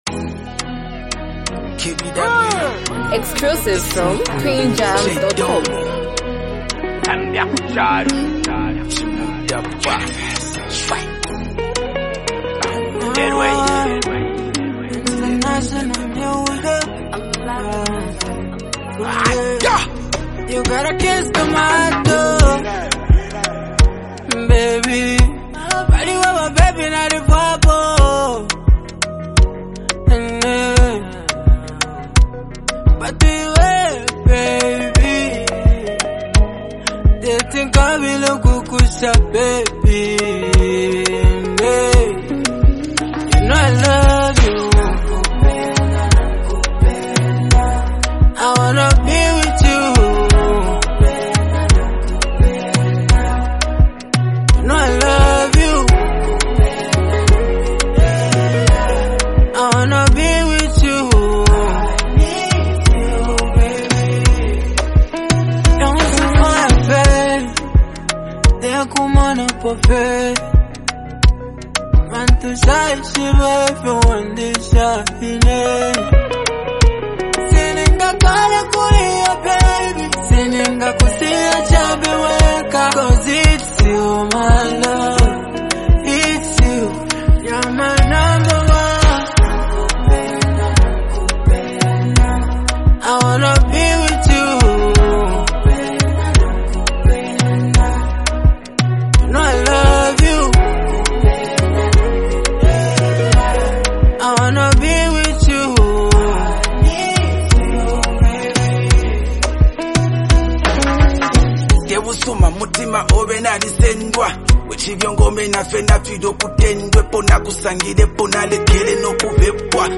a feel-good jam and a romantic dedication